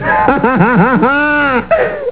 Laugh 2
laugh2.au